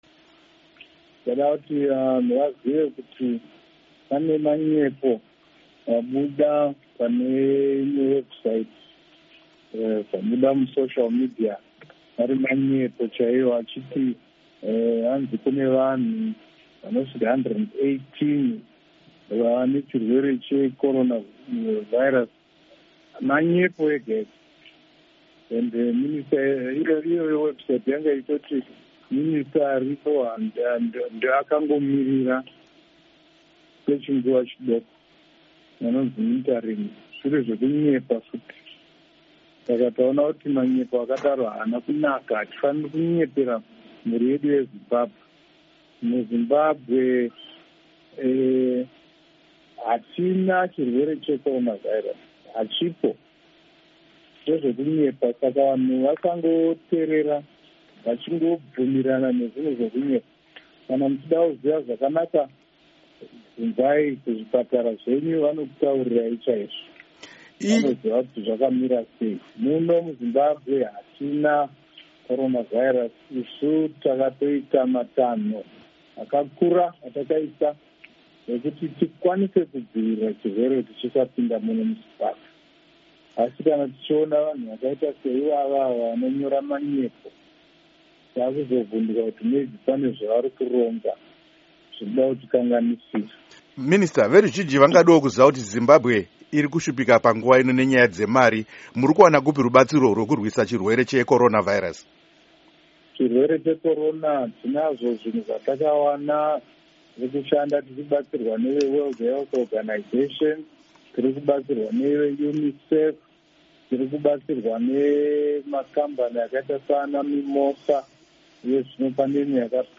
Izvi zvarambwa negurukota rezvehutano, Dr. Obadiah Moyo, muhurukuro yavaita neStudio7.
Hurukuro naDoctor Obadiah Moyo